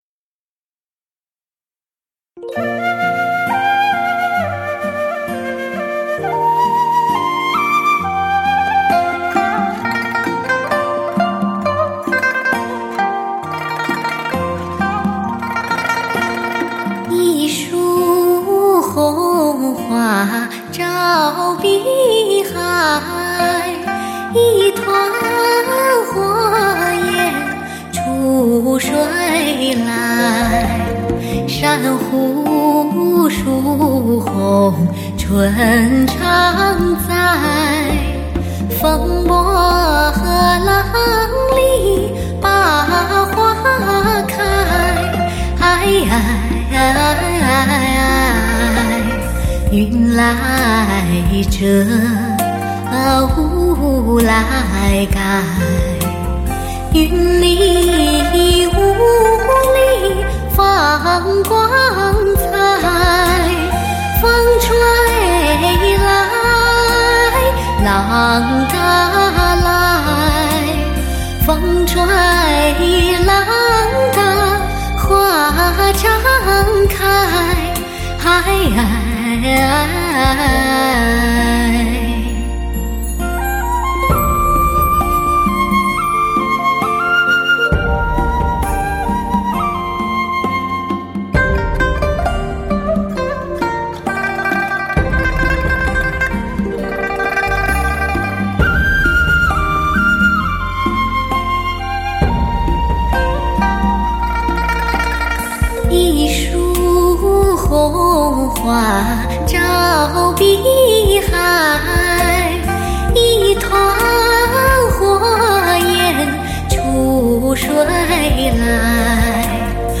开创革命性的STS+SRS全方位环绕HI-FIAUTO SOUND专业天世
专有STS Magix Mastering母带制作